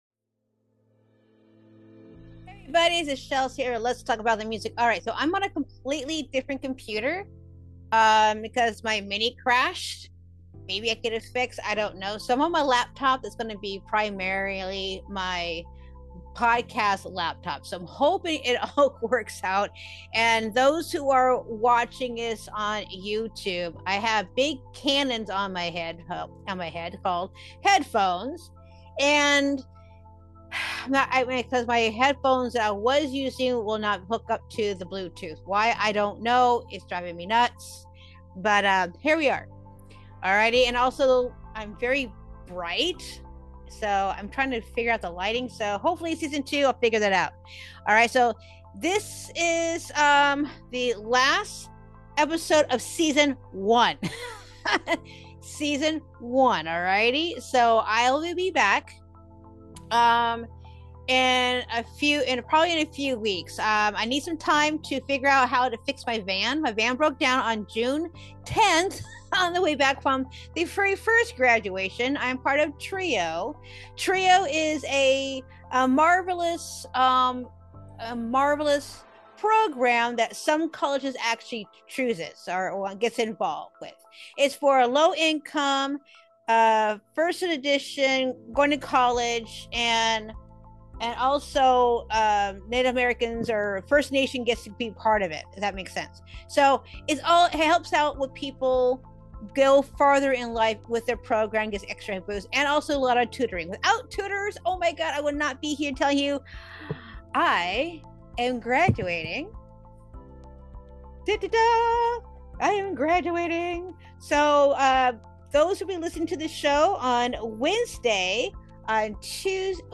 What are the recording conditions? EP 28 (Clips) – The Harmonica Showcase (Live at The Valley) Whether through intimate interviews, live performances, or navigating behind-the-scenes challenges, these moments shaped LTATM into what it is today.